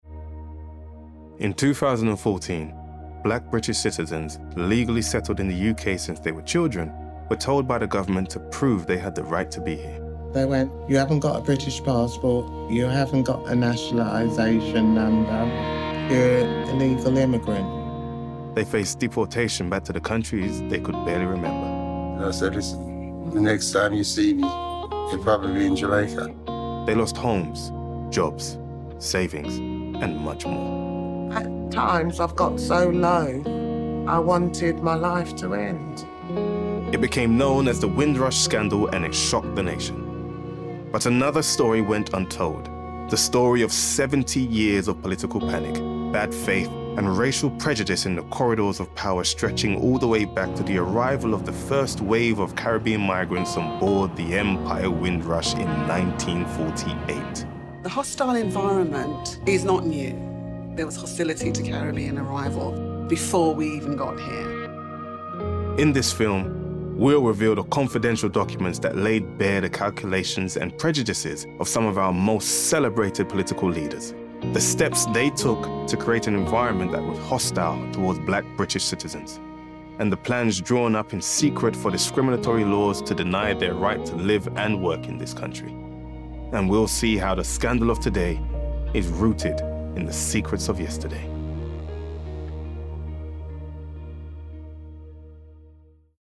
20/30's London, Natural/Warm/Relaxed
• Documentary